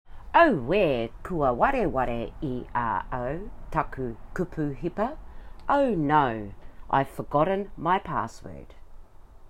How to pronounce this correctly